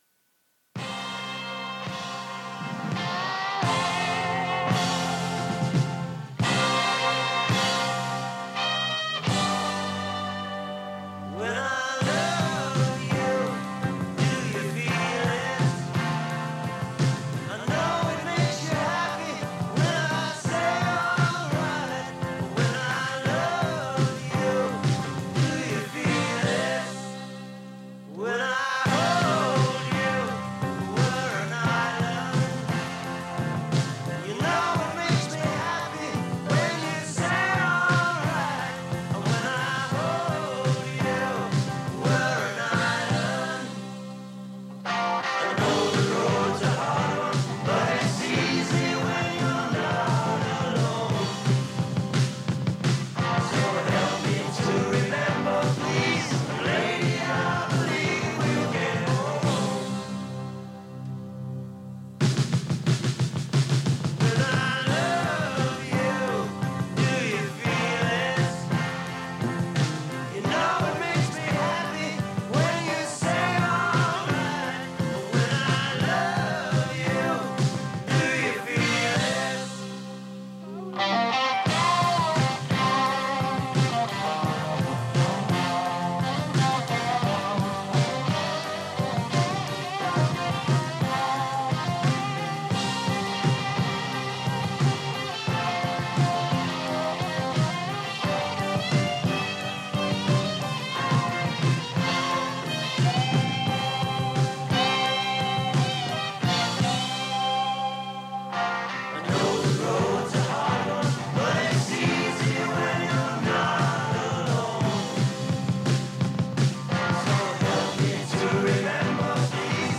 Are you ready for that good old rock n’ roll?
rockier, rougher, and wilder than ever before!